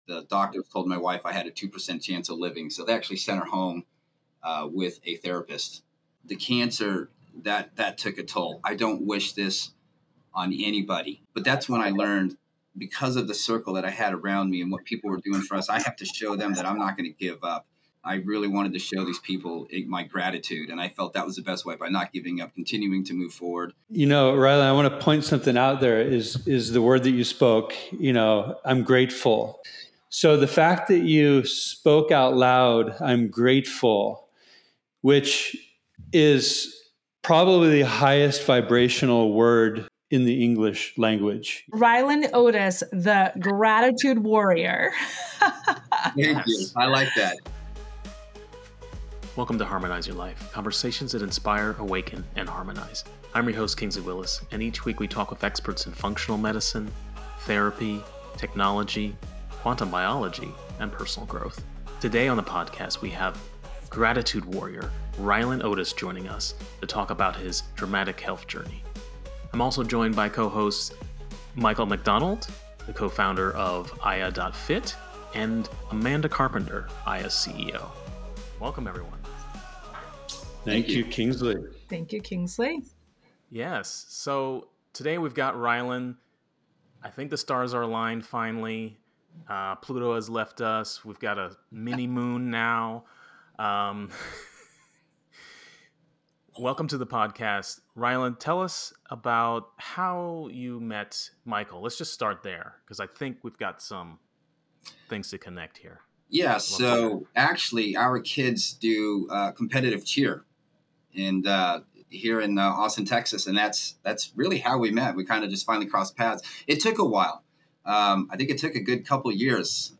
This immersive session features the enchanting Neptune Gong, an instrument known for its deep, resonant tones that facilitate profound relaxation and self-discovery.